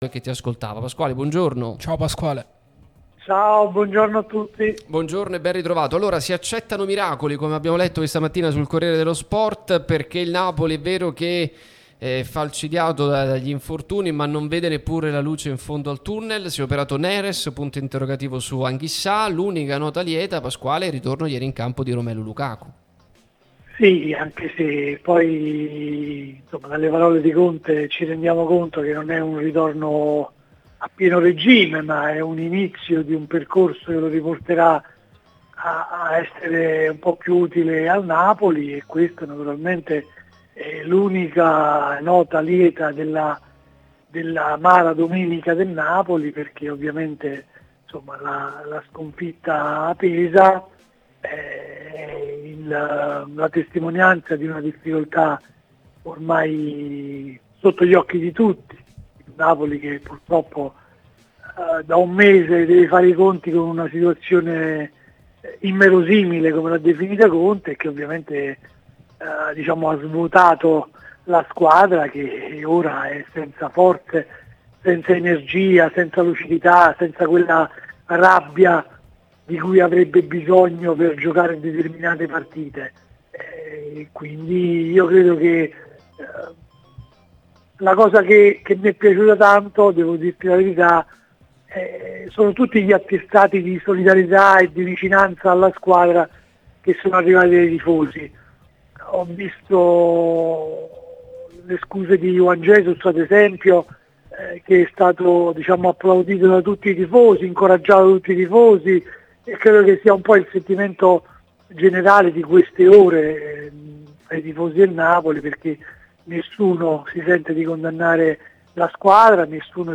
sulla nostra Radio Tutto Napoli, prima radio tematica sul Napoli, in onda...